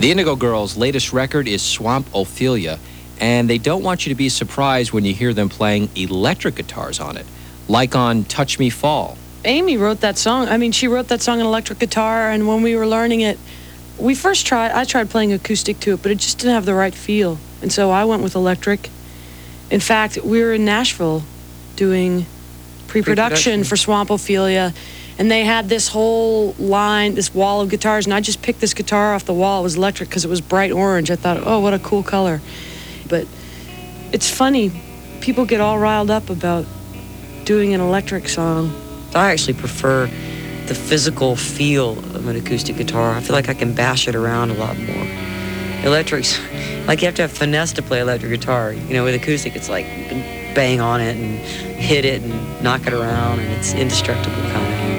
02. interview (0:55)